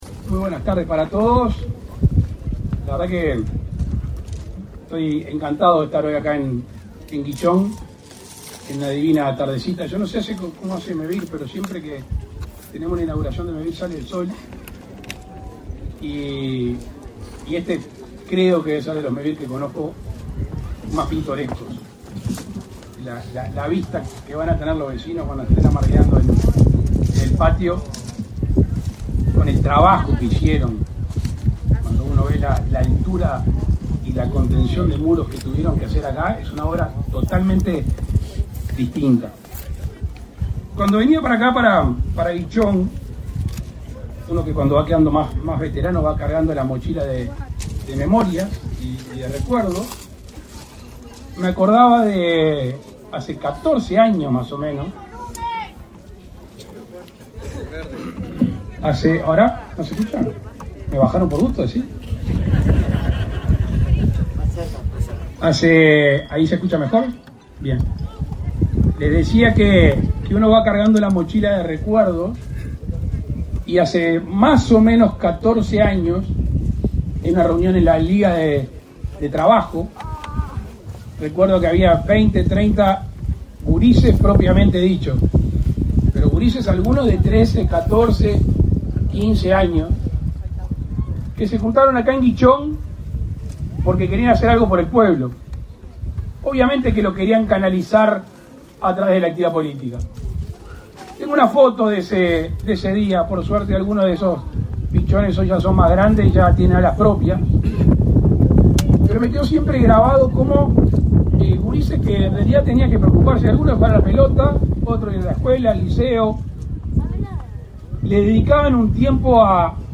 Palabras del presidente de la República, Luis Lacalle Pou
El presidente de la República, Luis Lacalle Pou, participó, este 14 de agosto, en la entrega de 61 soluciones habitacionales de Mevir en la localidad